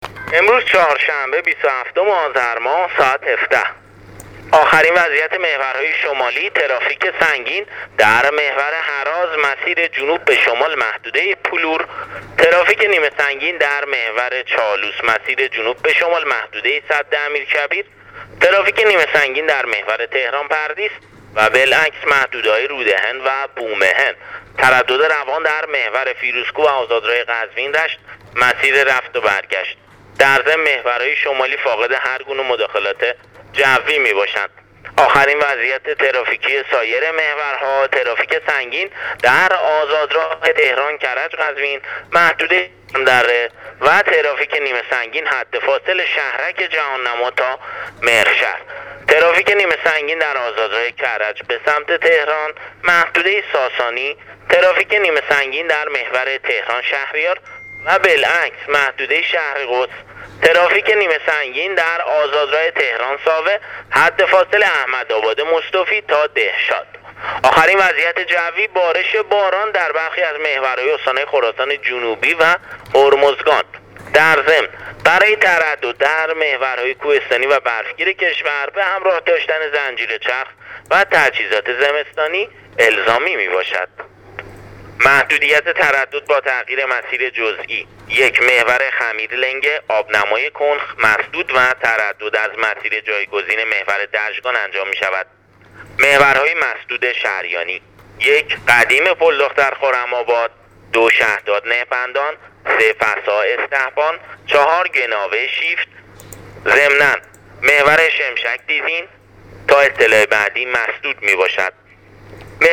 گزارش رادیو اینترنتی از آخرین وضعیت ترافیکی جاده‌ها تا ساعت ۱۷ بیست و هفتم آذر ۱۳۹۸: